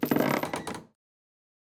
BridgeOpen.wav